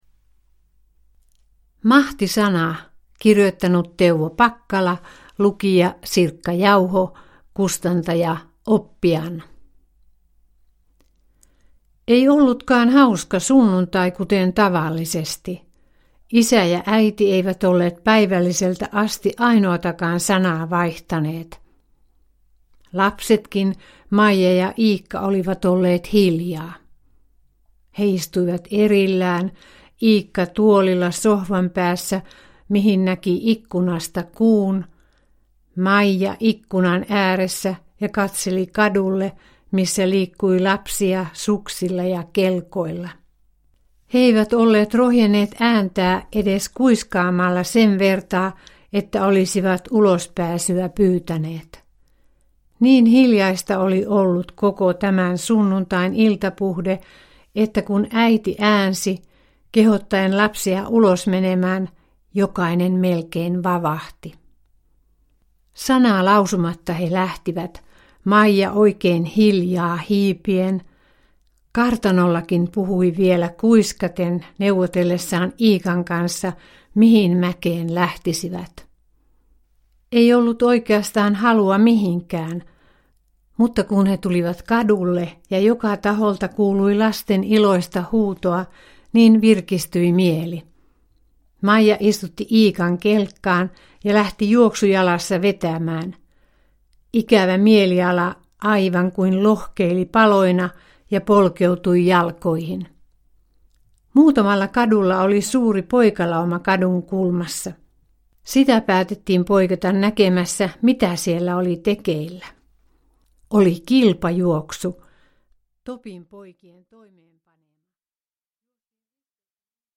Mahtisana – Ljudbok – Laddas ner